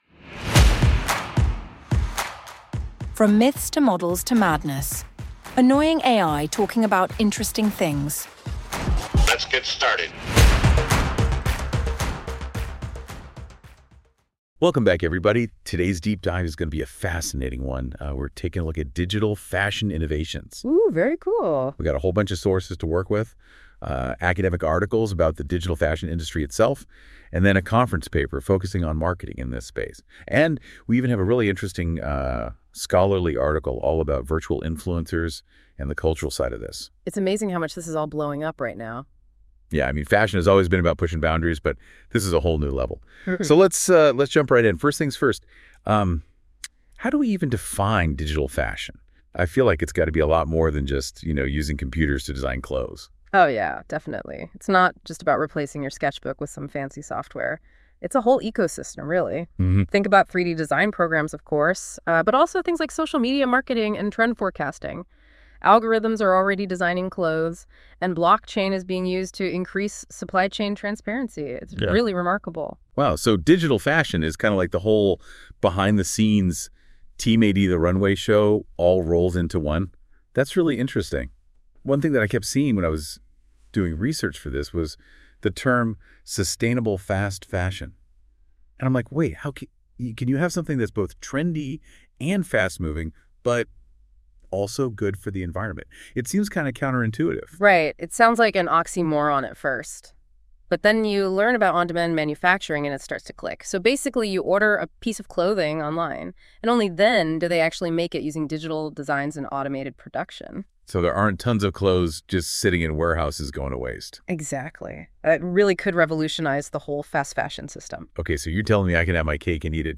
From Myths To Models To Madness: Annoying AI Talking about Interesting Things